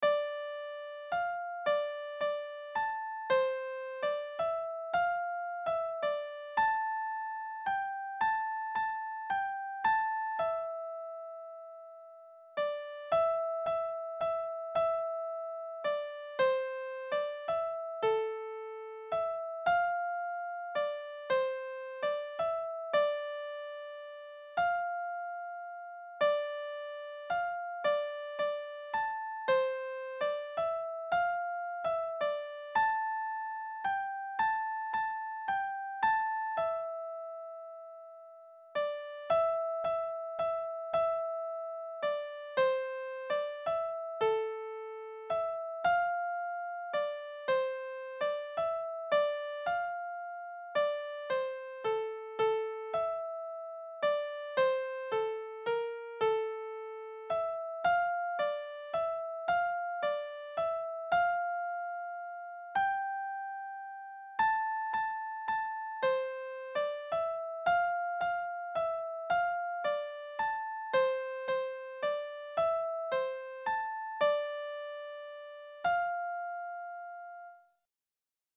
Morceaux de musique traditionnelle
irlandais33.mp3